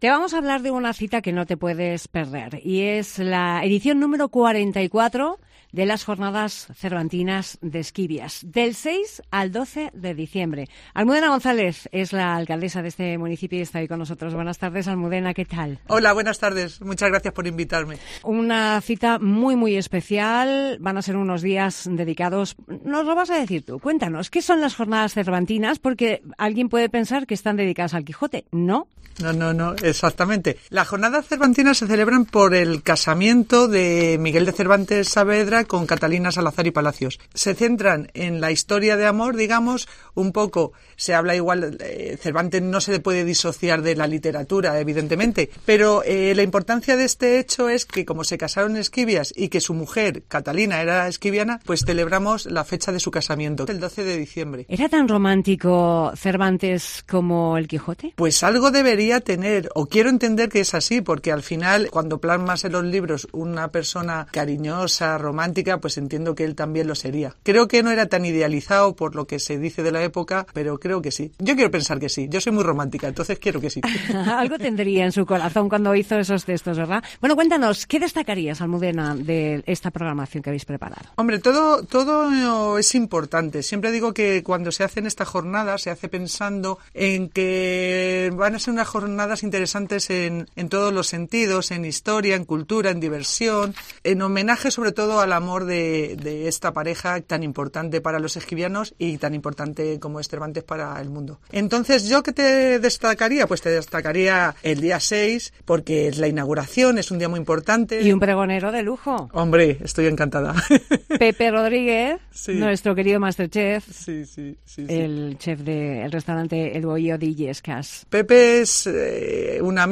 JORNADAS CERVANTINAS Entrevista a la alcaldesa de Esquivias, Almudena González